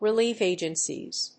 relief+agencies.mp3